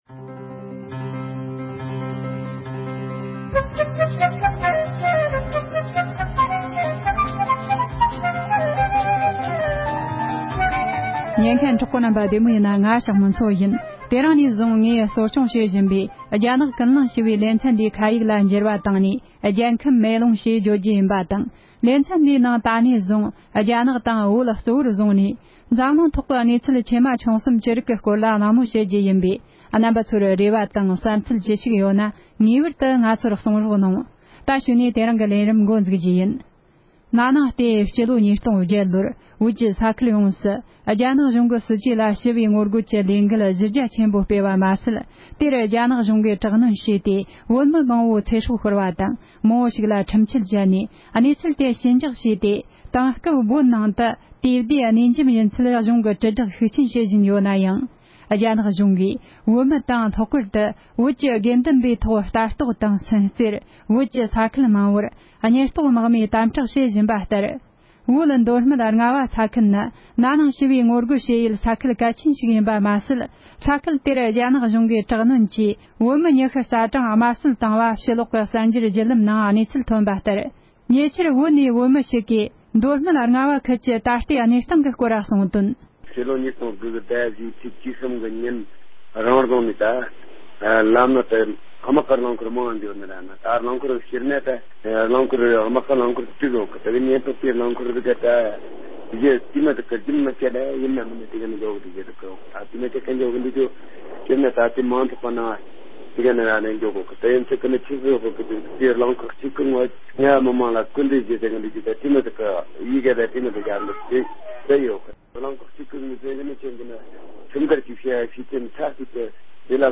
བོད་ནས་བོད་མི་ཞིག་གིས་བོད་མདོ་སྨད་རྔ་བ་ས་ཁུལ་གྱི་དེང་སྐབས་གནས་སྟངས་ངོ་སྤྲོད་གནང་བ།